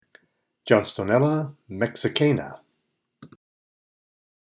Pronunciation/Pronunciación:
John-sto-nél-la  mex-i-cà-na